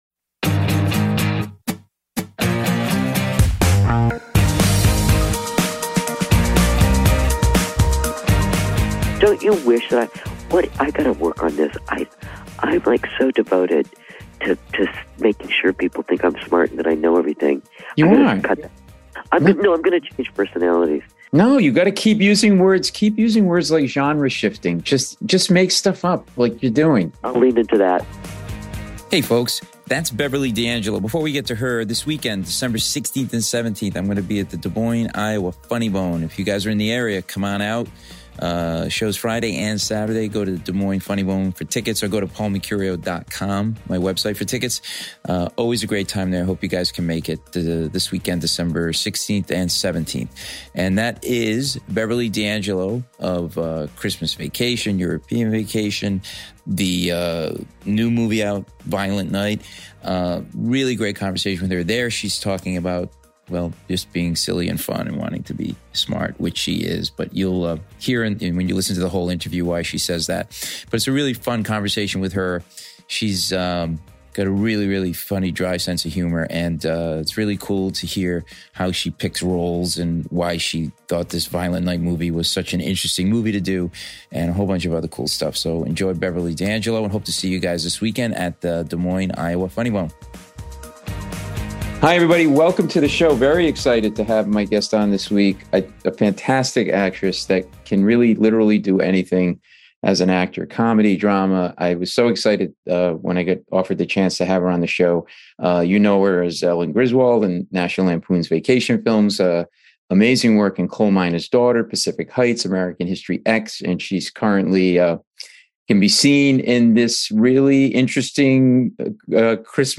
A really fun conversation with this film legend. Hear why she loved the script for "Violent Night" and the unique word she uses to describe the film.